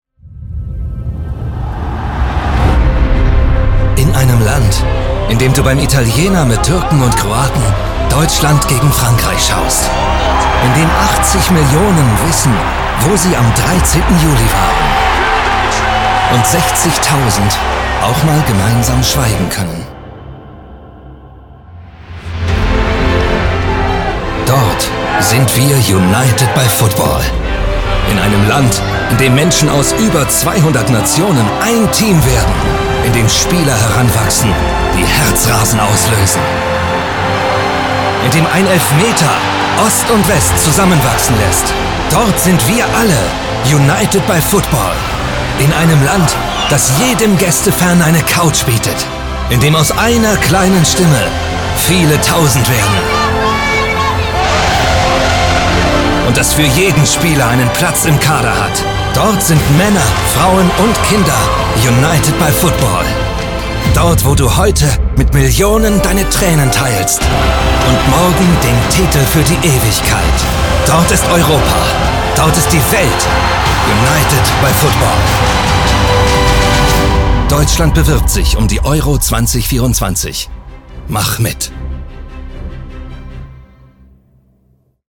•       IMAGEFILM